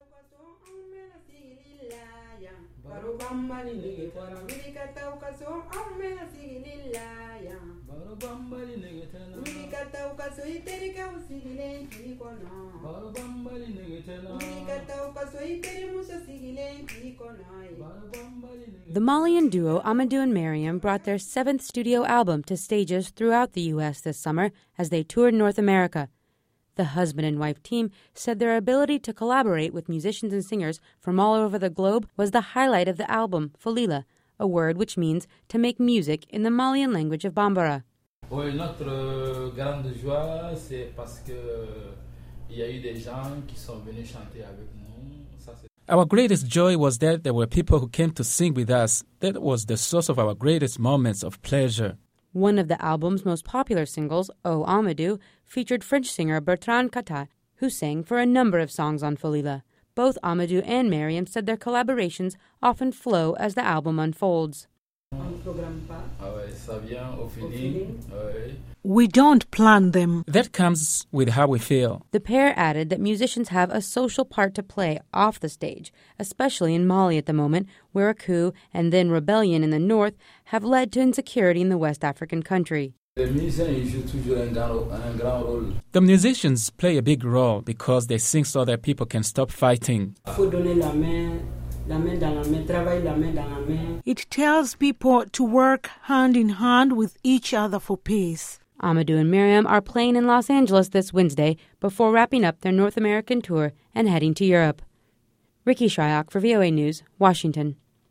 Mariam, from the duet Amadou and Mariam, sings before a Washington concert